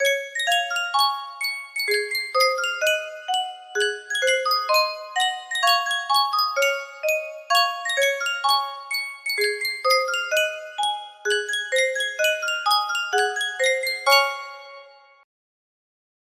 Yunsheng Music Box - Sing a Song of Sixpence 6017 music box melody
Full range 60